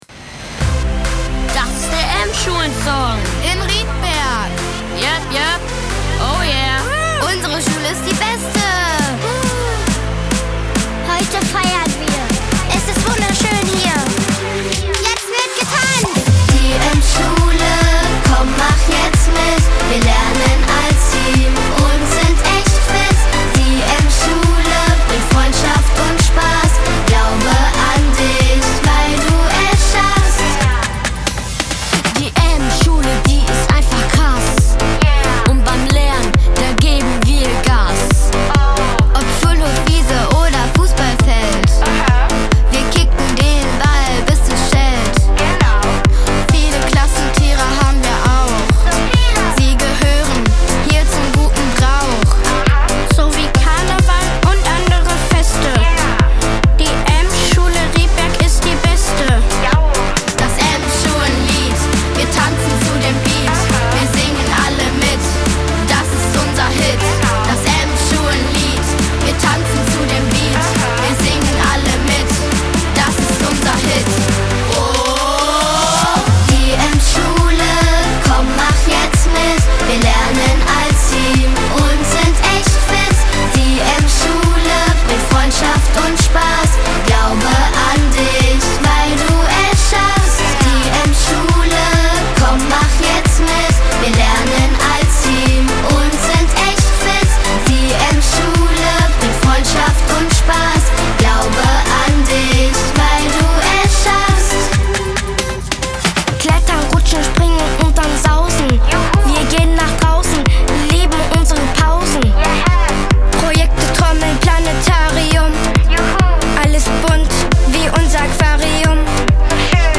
Einen Tag später konnte eine weitere Gruppe von 18 Kindern (je eins pro Klasse) das Lied einsingen.